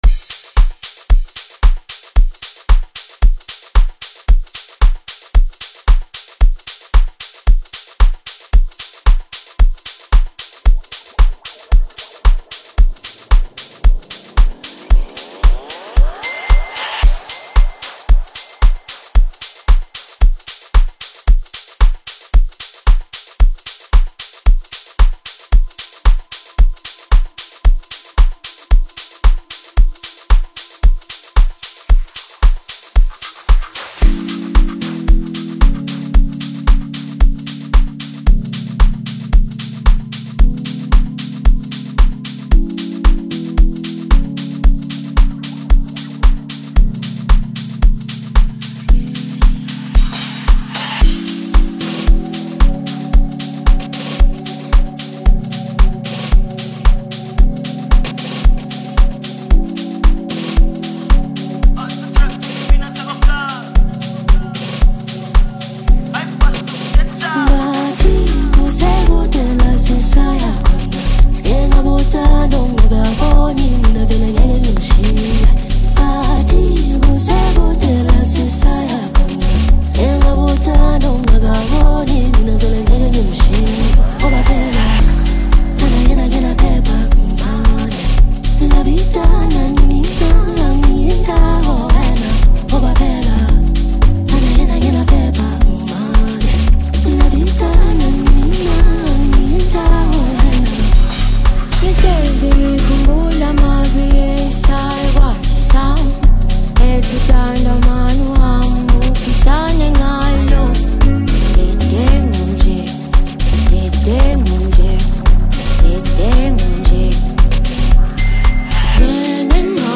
AMAPIANO Apr 07, 2026